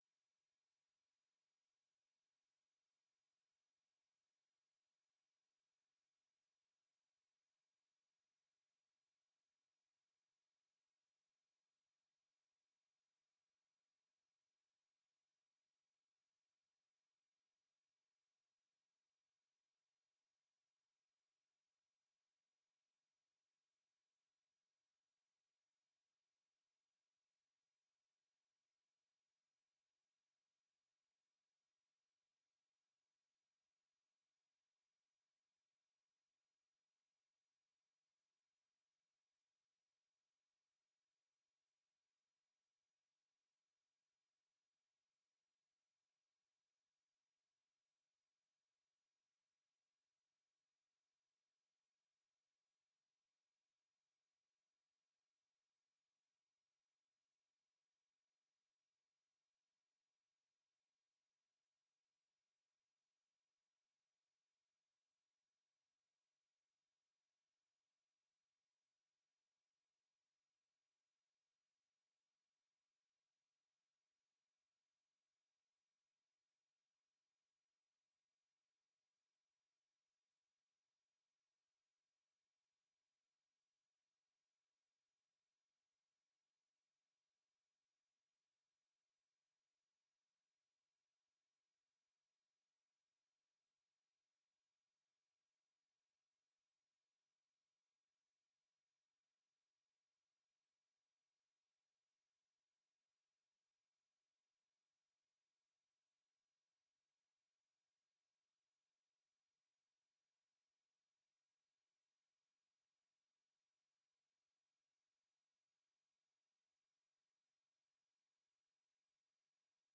informatieve raadsbijeenkomst 14 december 2023 19:30:00, Gemeente Doetinchem
Locatie: Raadzaal